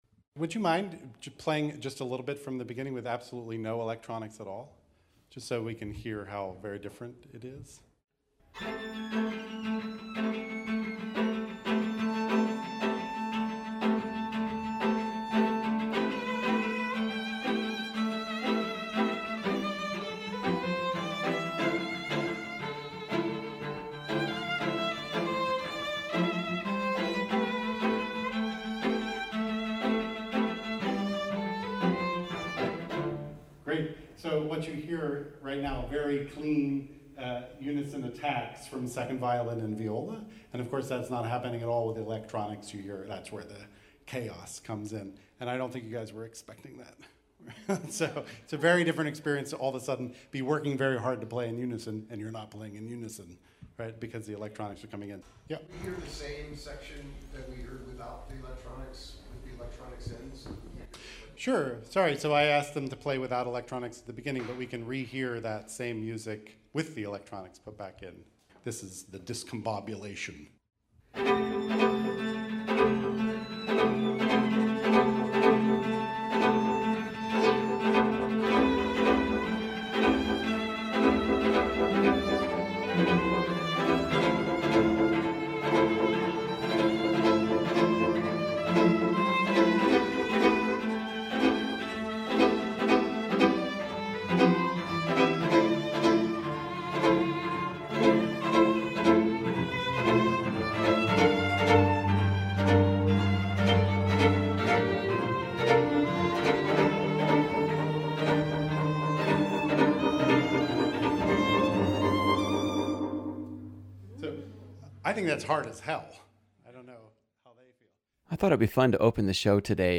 violin
viola
cello
clarinet
piano